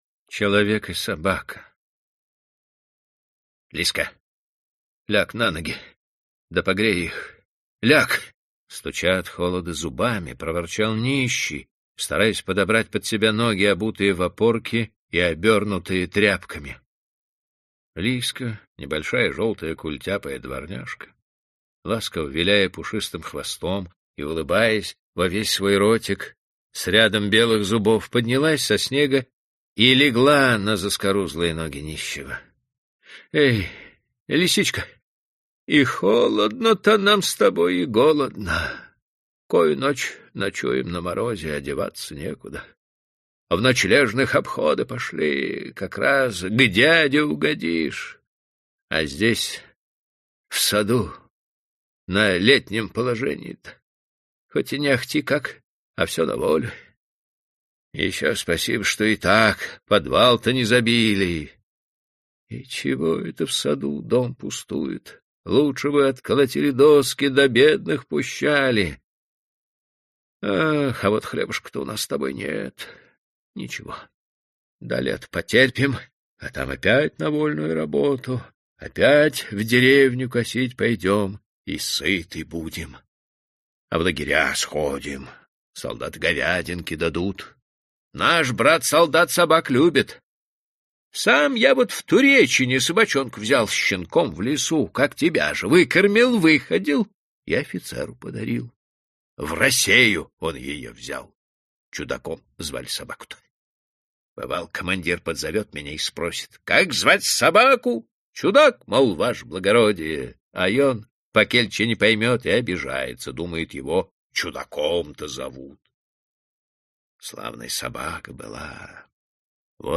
Аудиокнига По следам Гоголя и другие рассказы | Библиотека аудиокниг